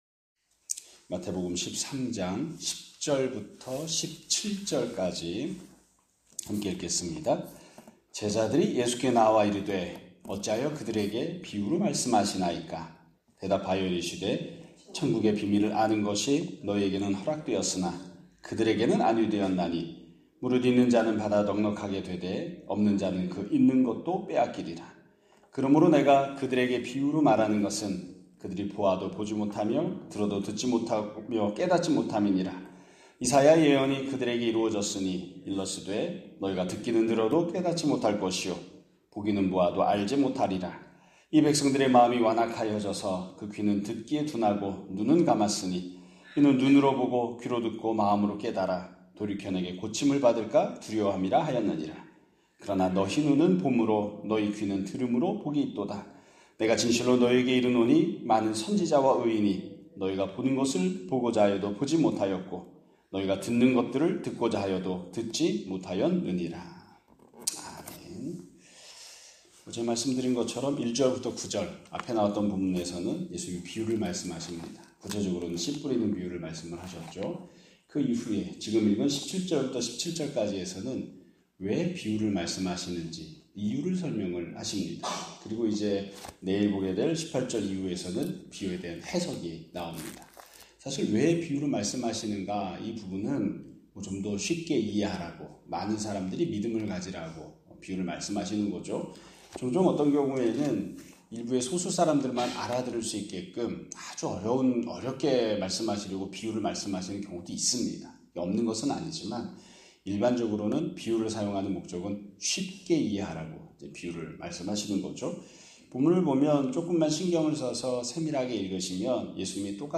2025년 9월 25일 (목요일) <아침예배> 설교입니다.